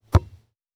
Foley Sports / Football - Rugby / Field Goal Kick Normal.wav
Field Goal Kick Normal.wav